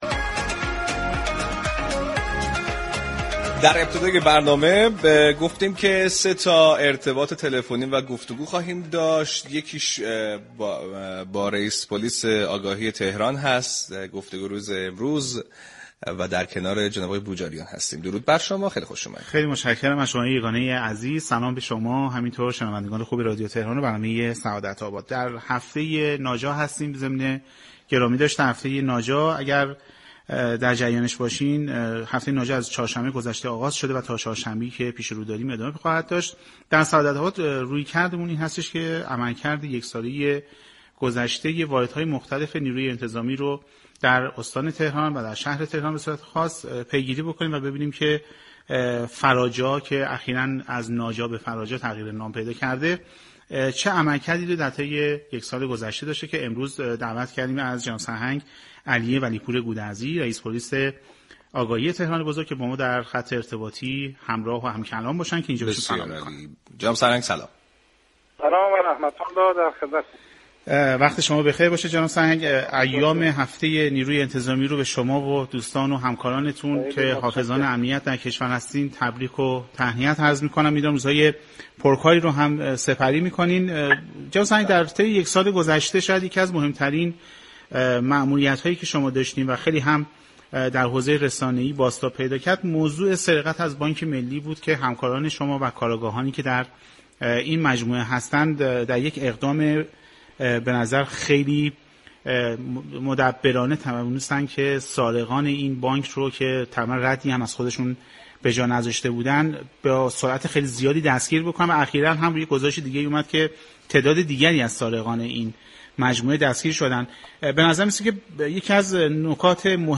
سرهنگ علی ولی پور گودرزی رئیس پلیس آگاهی تهران بزرگ در گفت‌وگو با سعادت آباد رادیو تهران